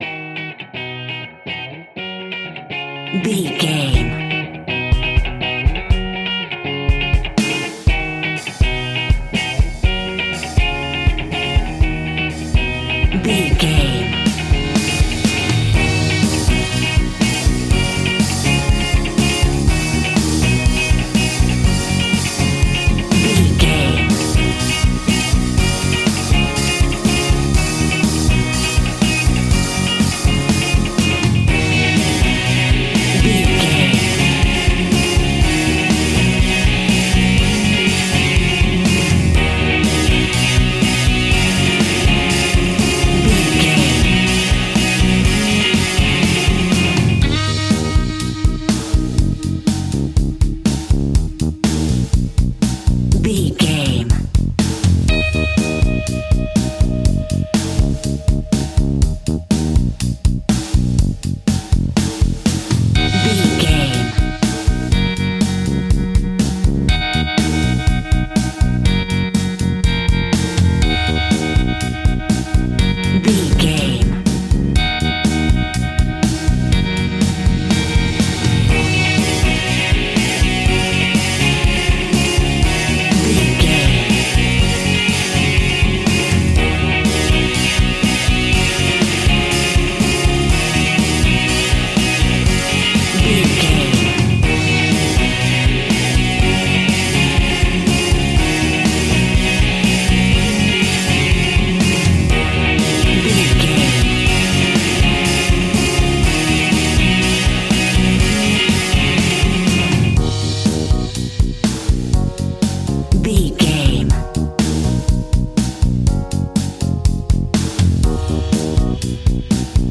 Ionian/Major
E♭
pop rock
indie pop
fun
energetic
uplifting
electric guitar
Distorted Guitar
Rock Bass
Rock Drums
hammond organ